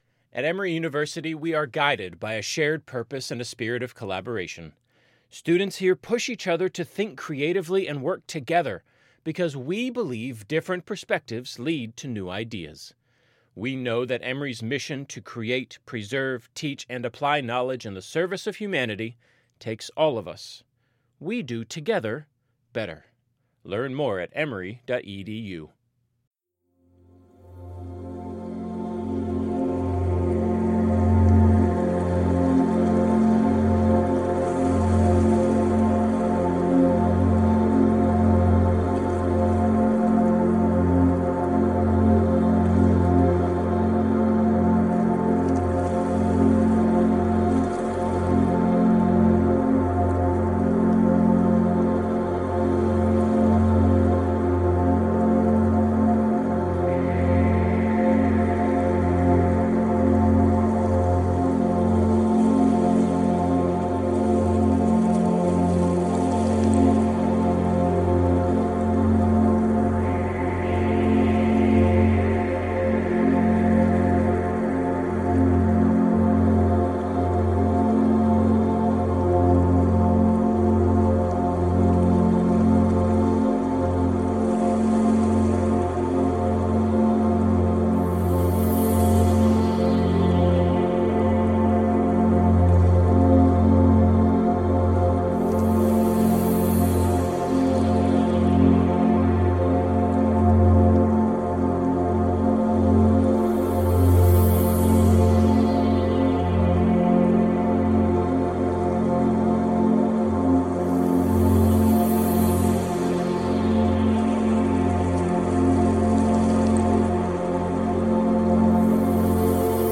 9hz - Alpha Binaural Beats for Creative Thinking